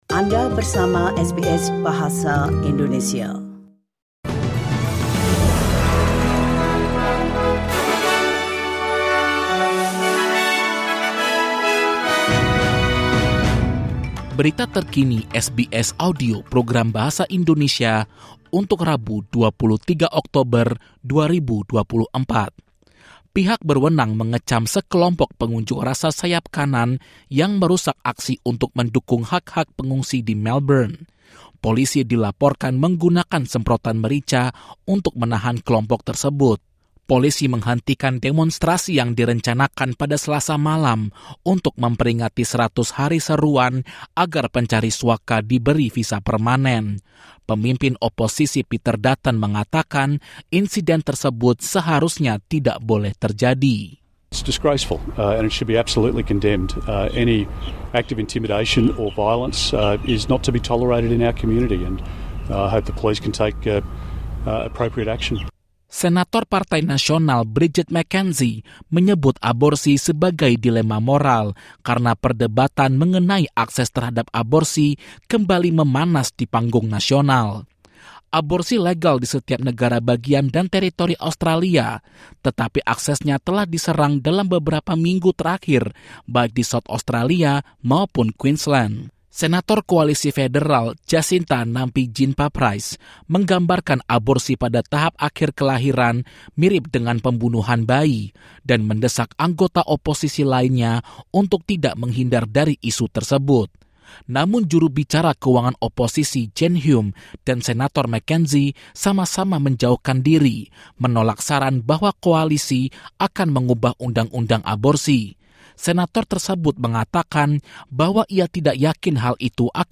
Berita terkini SBS Audio Program Bahasa Indonesia – 1 Jan 2024.